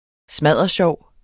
Udtale [ ˈsmaðˀʌˈɕɒwˀ ]